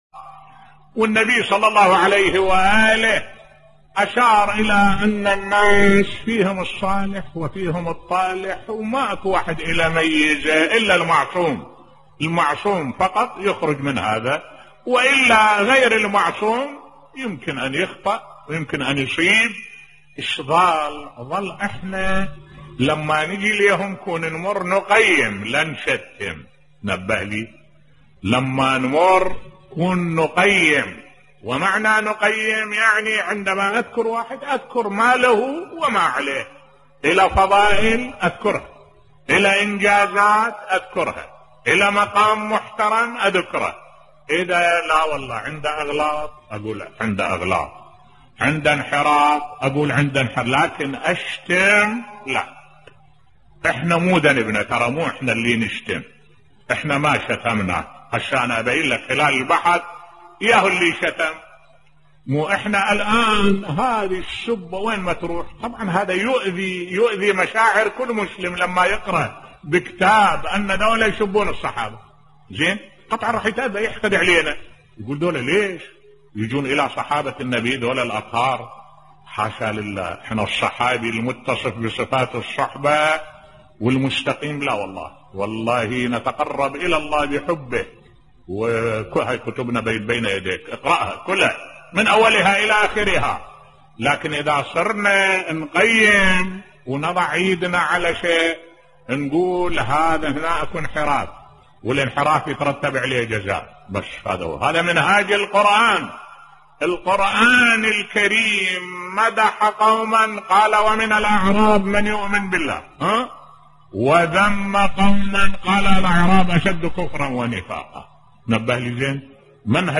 ملف صوتی حاشا لله أن نشتم الصحابة الأطهار بصوت الشيخ الدكتور أحمد الوائلي